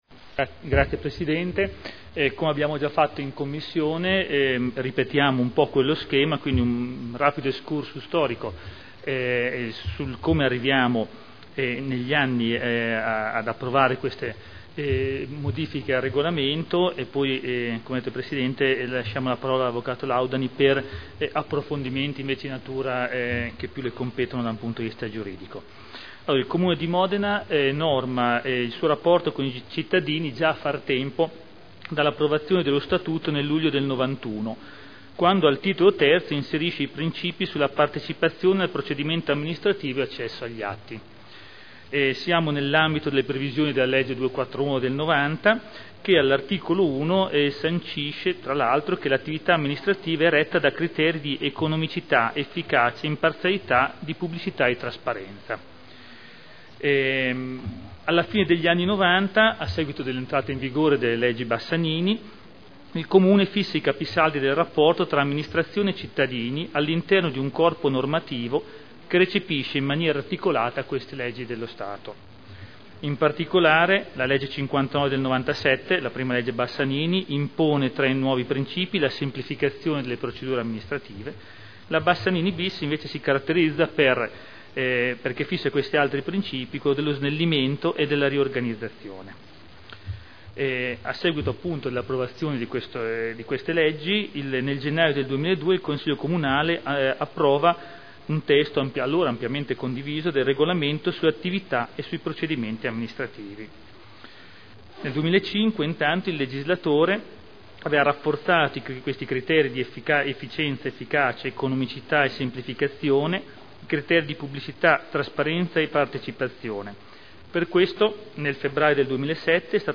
Seduta del 07/06/2010